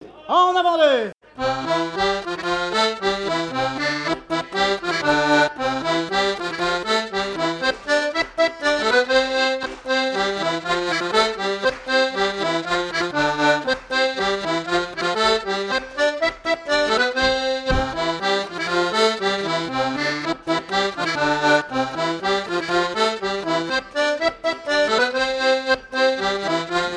danse : branle : avant-deux
Fête de l'accordéon
Pièce musicale inédite